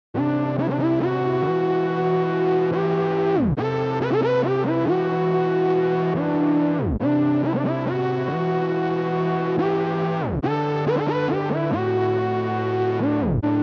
ORBIT_RTB_140_chord_synth_loop_chainsaw_Emin